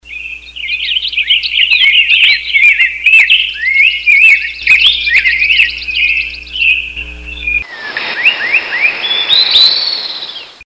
Soothing forest birds singing ringtone free download
Animals sounds